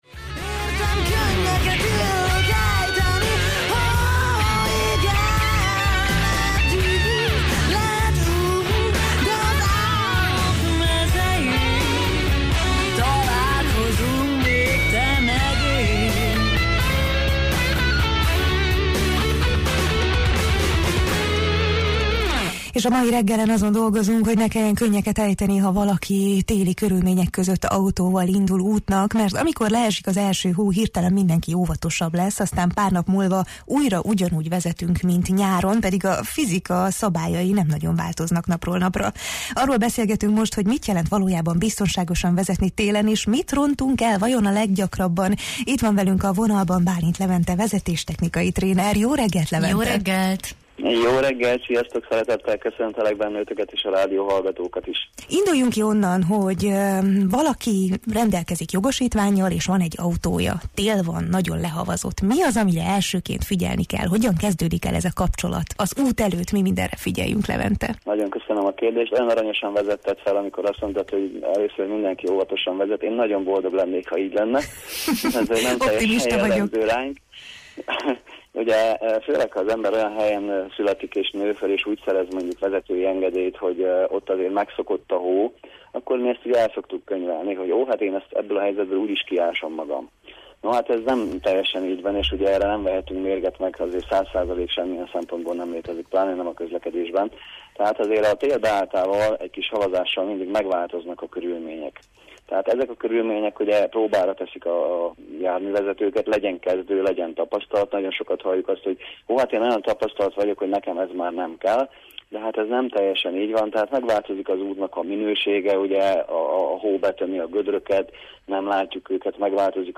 Ma reggel a Jó reggelt, Erdély! műsorában a tél valódi arcáról beszélgettünk – arról, amely az utakon köszön vissza ránk.
Nem maradt ki az sem, mi az, aminek télen kötelezően ott kell lennie az autóban: olyan eszközök és felszerelések, amelyek nemcsak kényelmet, hanem adott esetben biztonságot vagy akár megoldást jelenthetnek egy váratlan helyzetben. Hasznos tanácsok, életmentő tippek és téli túlélőpraktikák – erről szólt a mai beszélgetés a Jó reggelt, Erdély!-ben: